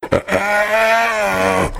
c_hyena_hit3.wav